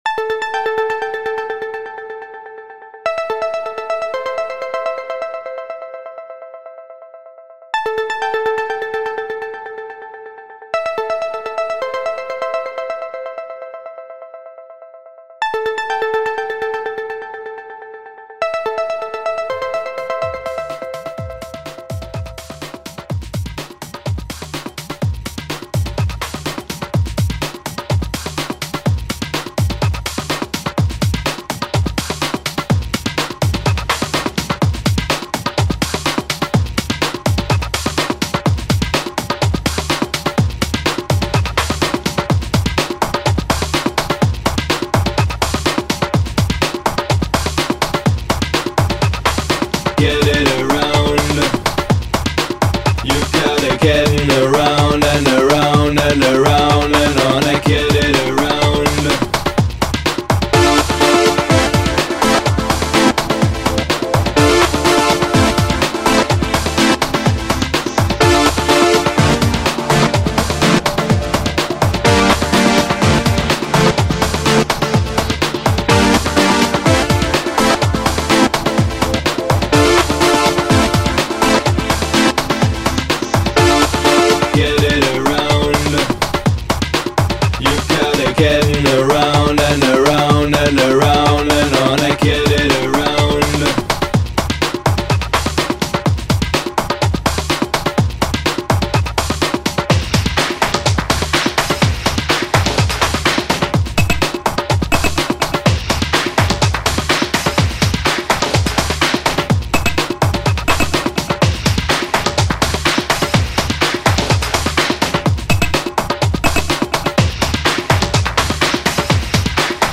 Home > Music > Electronic > Running > Chasing > Restless